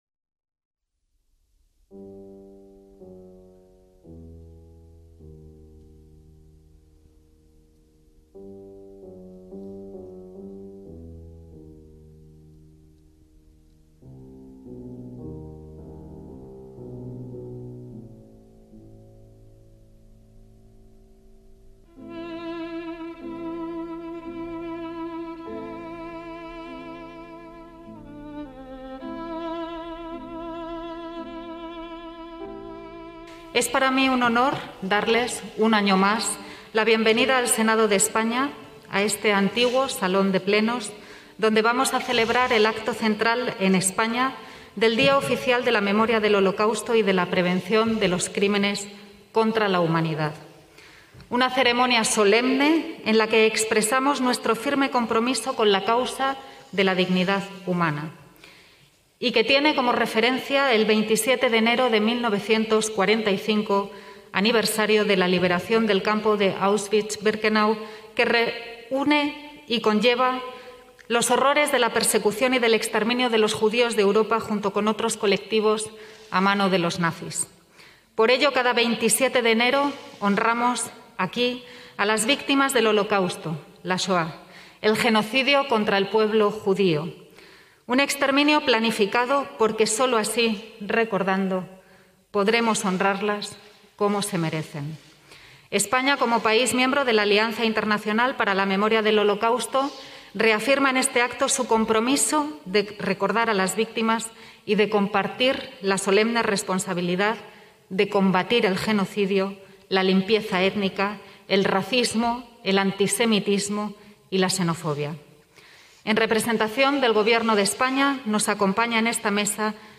Acto de estado en el Día Oficial de la memoria del Holocausto y la prevención de los crímenes contra la humanidad (Senado, Madrid, 27/1/2021)
ACTOS EN DIRECTO